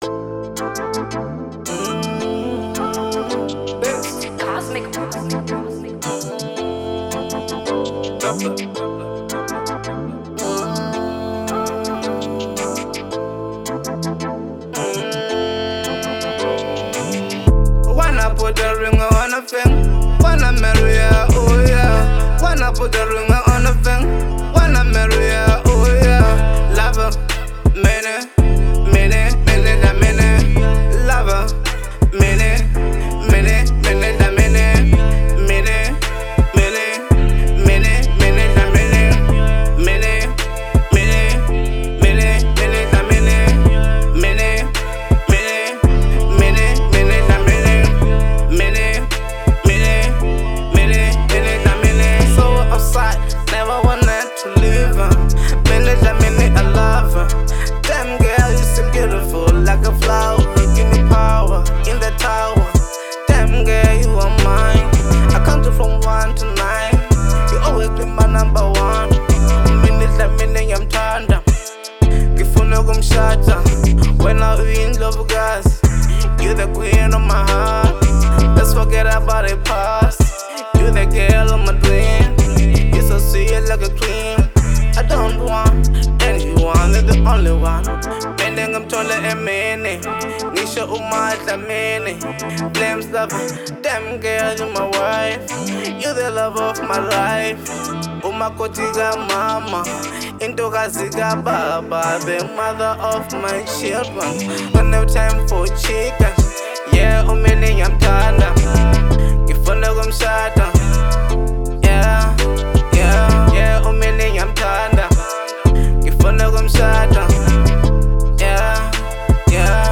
03:51 Genre : Hip Hop Size